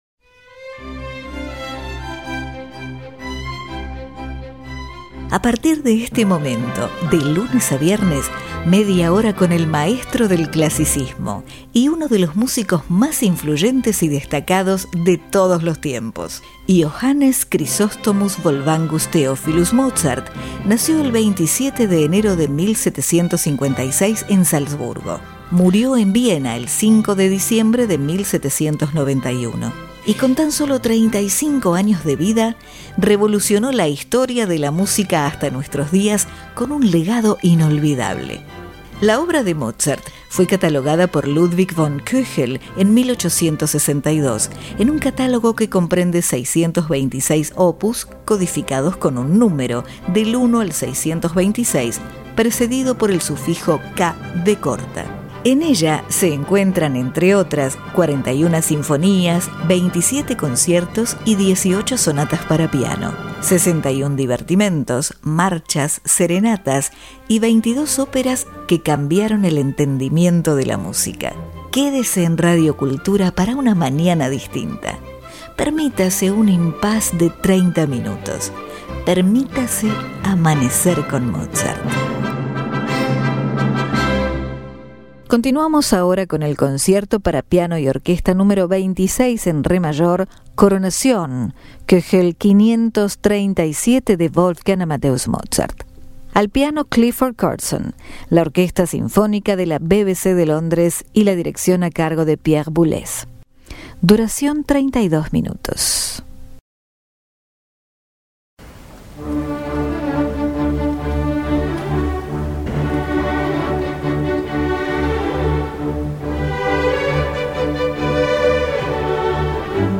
Concierto Para Piano Y Orquesta
En Re Mayor
Clifford Curzon (Piano) Orquesta Sinfónica De La BBC De Londres Pierre Boulez